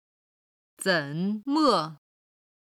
ただ軽音部分の音源がないので、元々の単語の四声にしています。